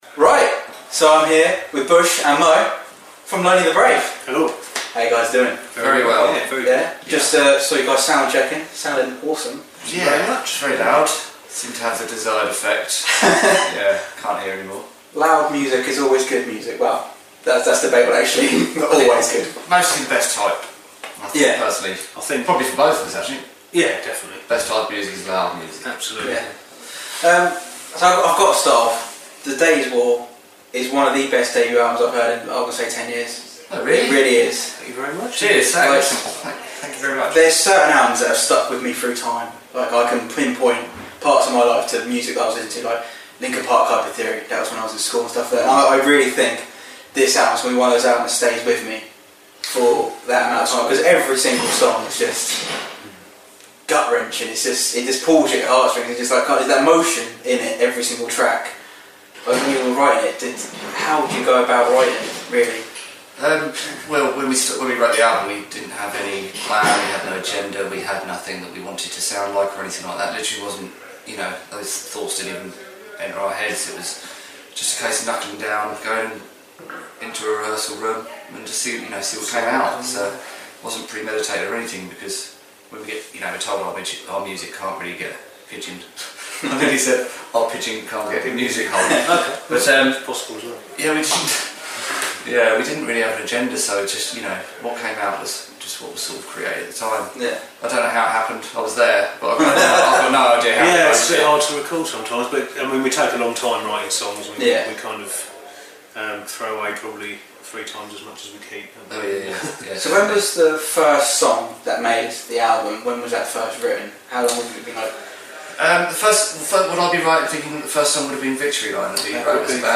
Lonely the Brave interview March 2015
I caught up with the wonderful Lonely the Brave at the Wedgewood rooms in Portsmouth to play a little game called Naming the Brave!